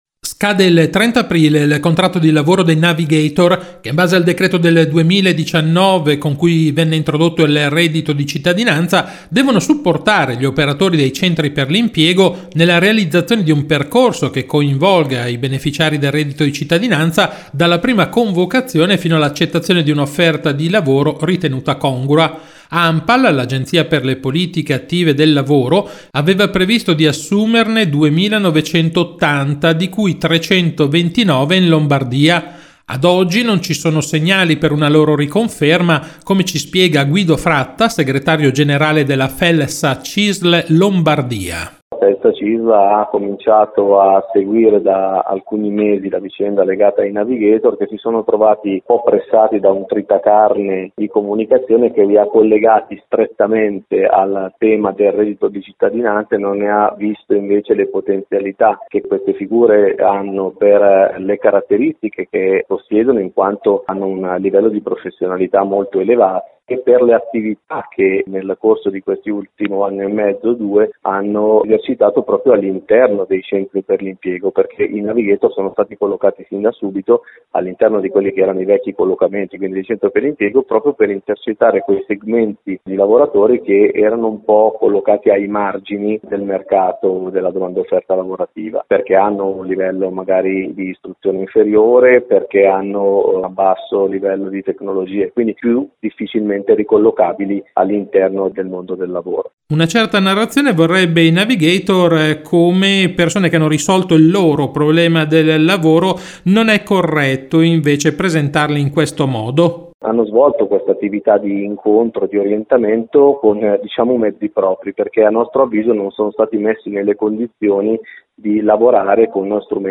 Di seguito la puntata del 12 febbraio di RadioLavoro, la rubrica d’informazione realizzata in collaborazione con l’ufficio stampa della Cisl Lombardia e in onda ogni quindici giorni su RadioMarconi il venerdì alle 12.20, in replica alle 18.10.